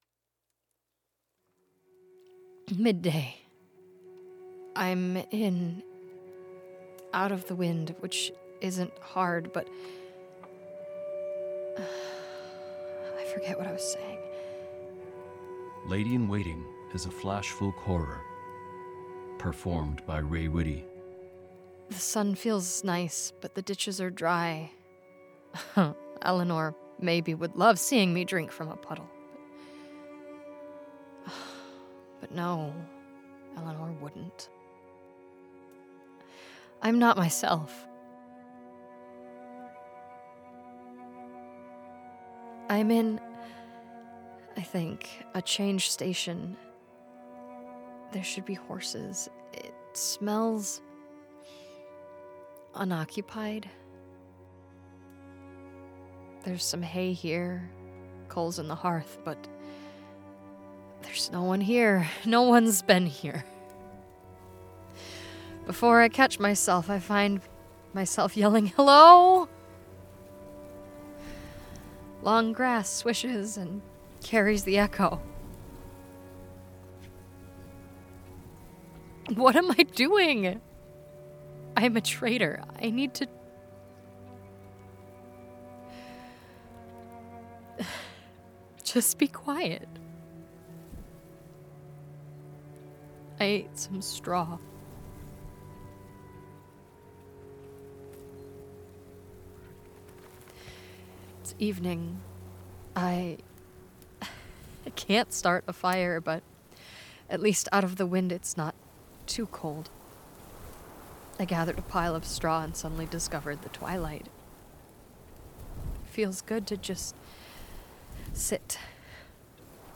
Lady in Waiting is a flash folk-horror told through prayers.
This is an immersive audio drama. So, turn your volume up.